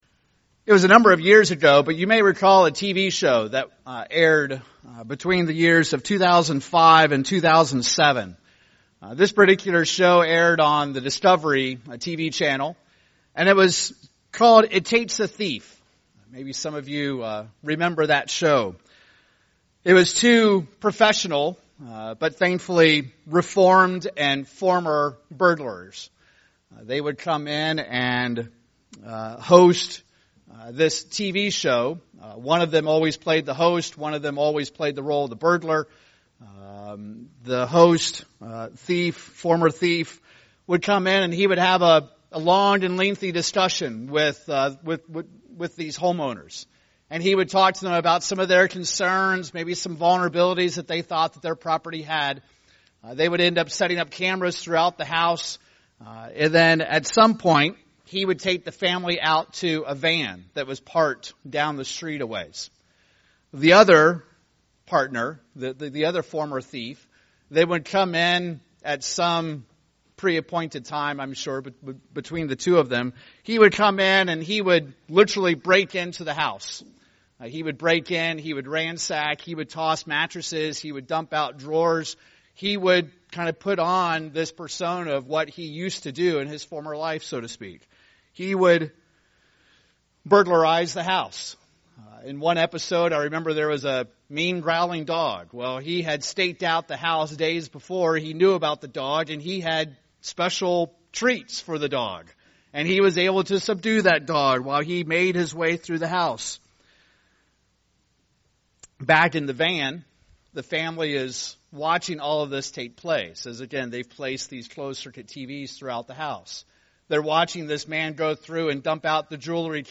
Holy Day Services Studying the bible?